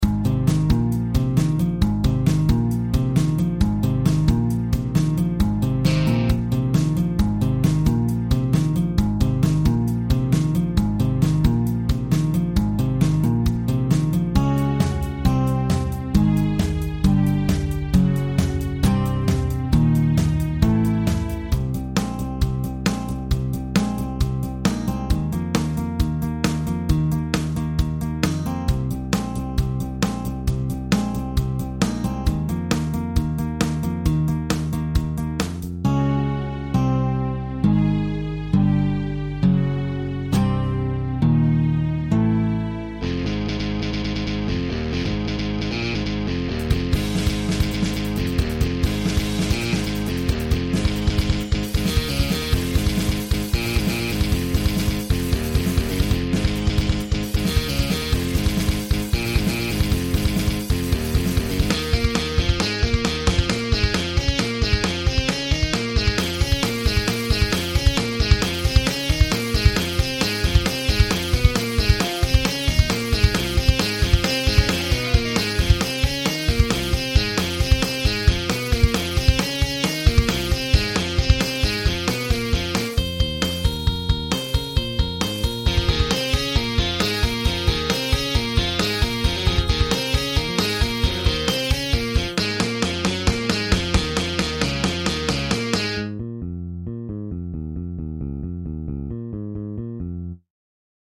“这首歌和南瓜派没有任何关系，它带有 80 年代老式垃圾摇滚的痕迹。